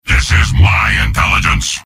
Giant Robot lines from MvM. This is an audio clip from the game Team Fortress 2 .
{{AudioTF2}} Category:Soldier Robot audio responses You cannot overwrite this file.